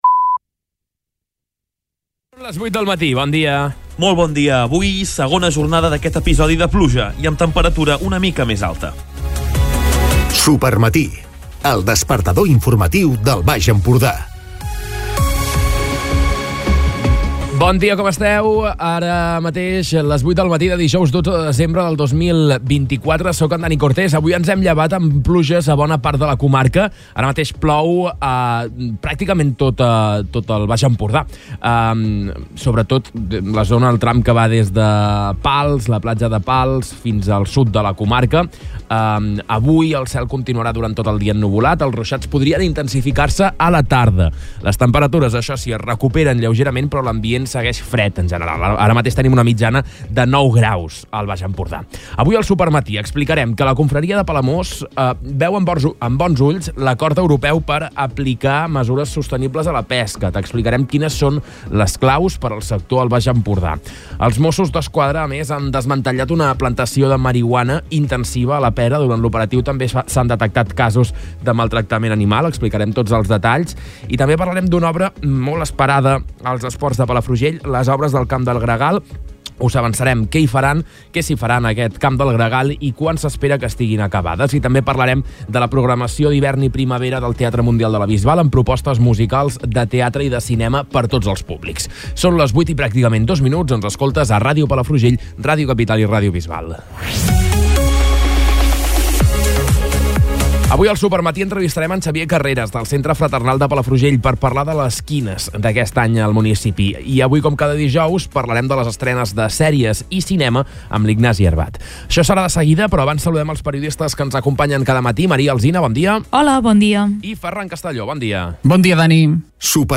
Escolta l'informatiu d'aquest dijous